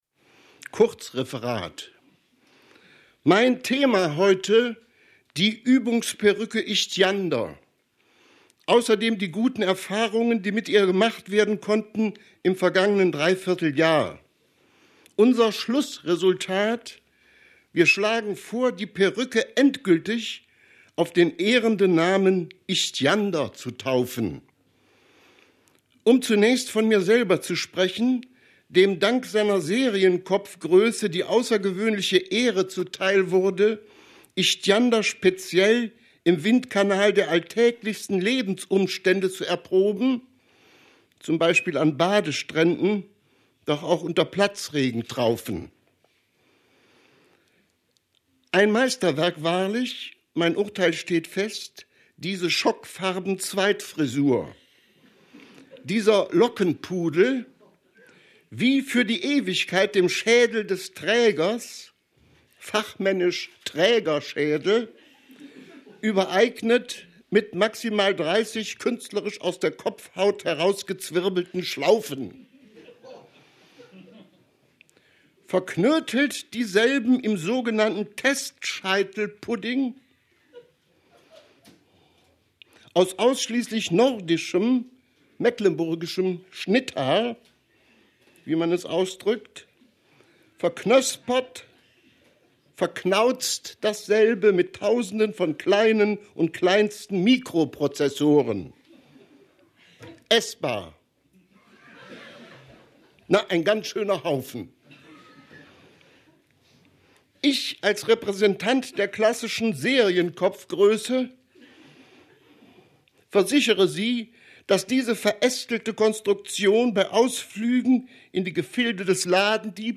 Lesung von Adolf Endler in der literaturWERKstatt Berlin zur Sommernacht der Lyrik – Gedichte von heute